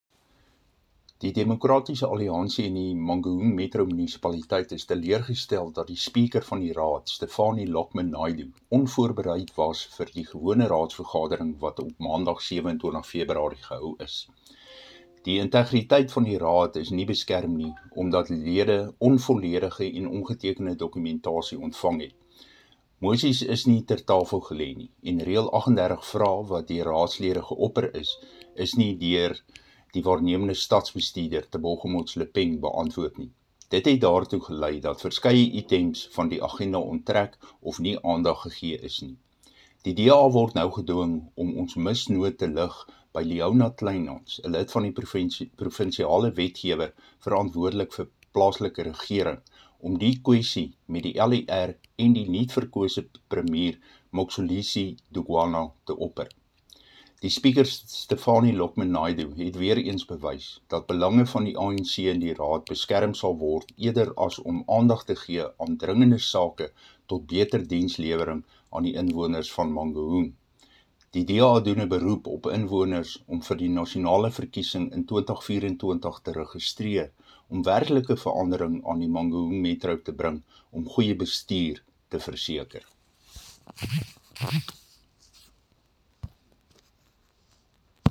Afrikaans soundbites by Cllr Dirk Kotze.